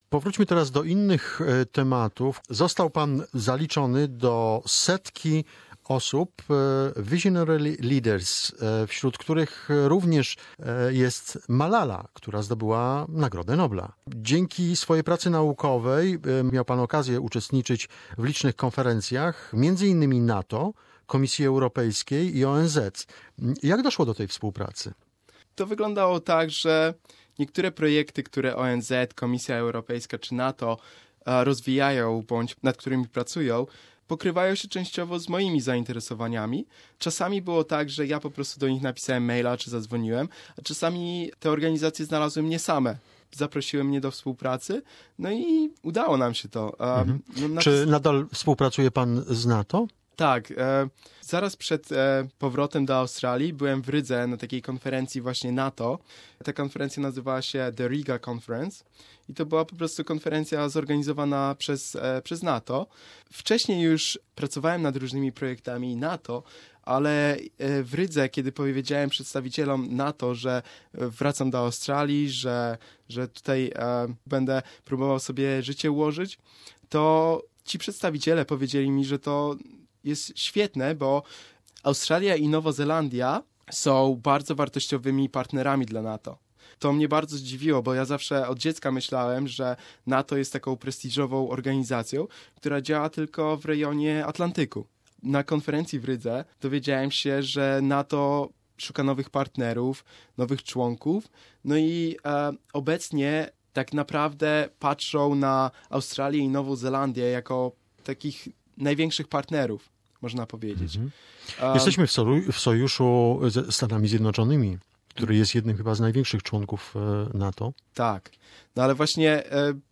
Second part of conversation